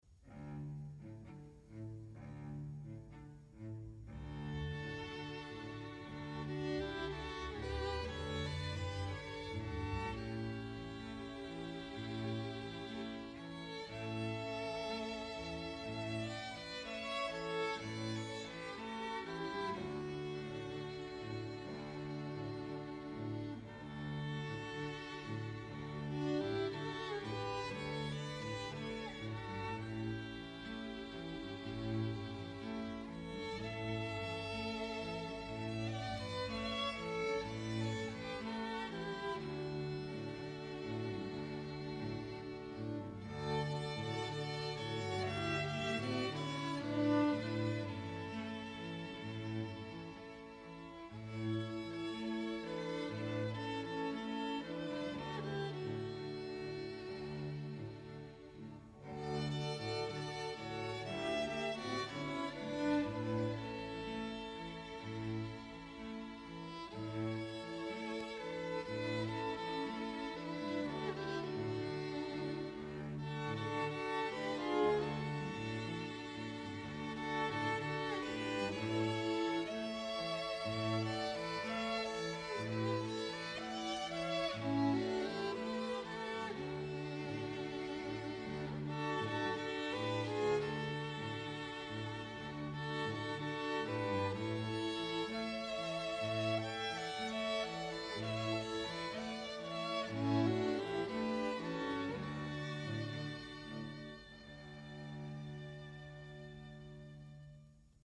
String Quartet based in the North-West